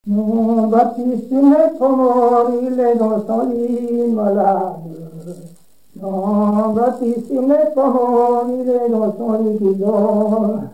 Genre brève
Pièce musicale inédite